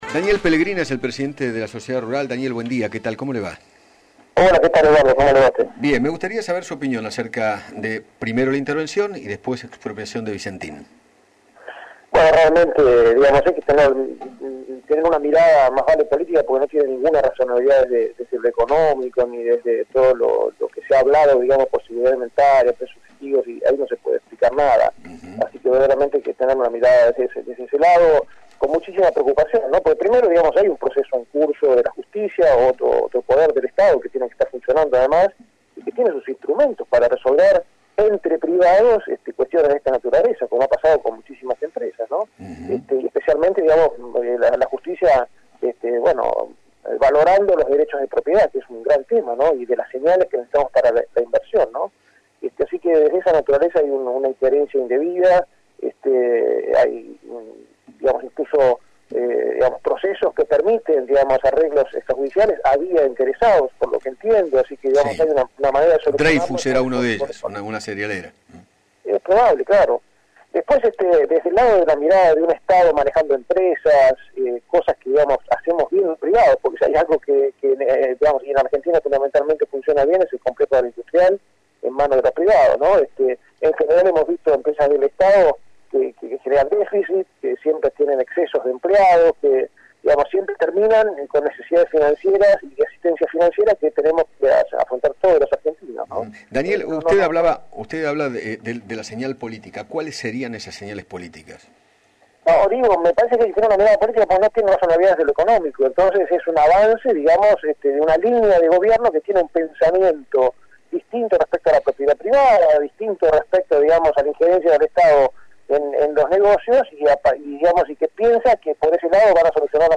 dialogó con Eduardo Feinmann sobre la decisión del gobierno de intervenir y expropiar la empresa agroindustrial.